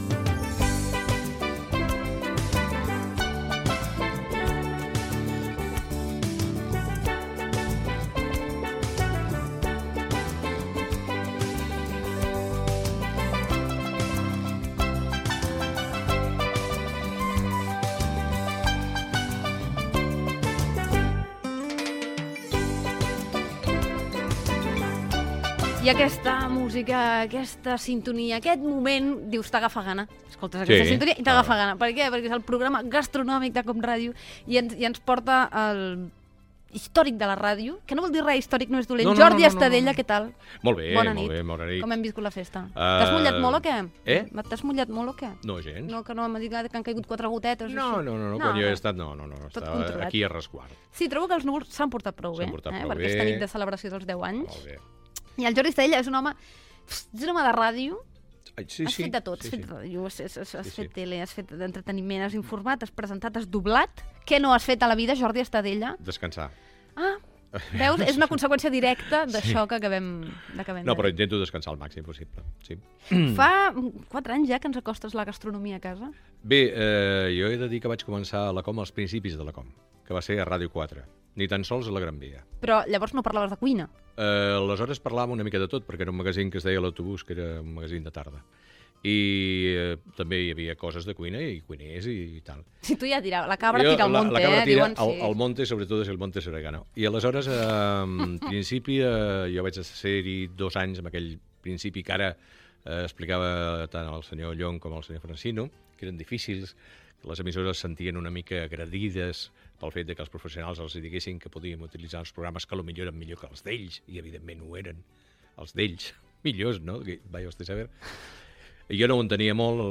Especial del 10 aniversari de COM Ràdio.
Entrevista a Jordi Estadella, presentador de "De boca en boca" a COM Ràdio.